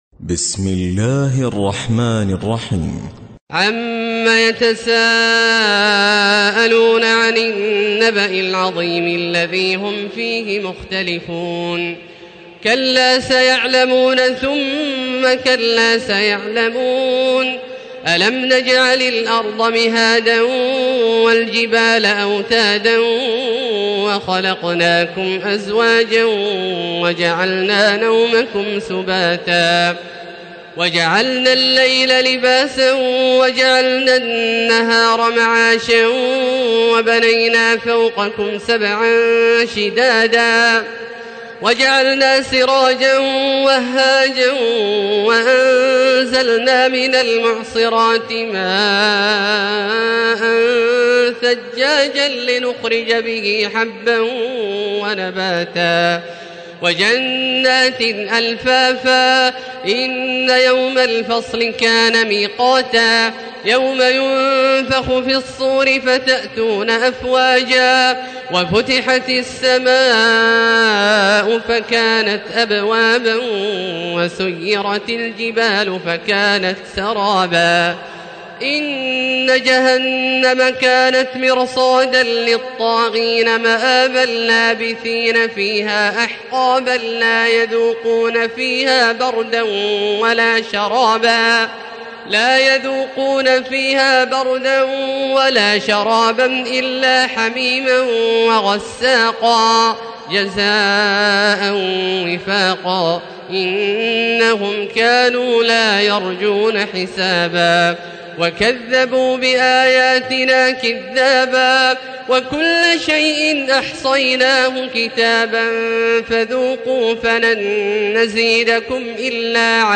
تراويح ليلة 29 رمضان 1437هـ من سورة النبأ الى البينة Taraweeh 29 st night Ramadan 1437H from Surah An-Naba to Al-Bayyina > تراويح الحرم المكي عام 1437 🕋 > التراويح - تلاوات الحرمين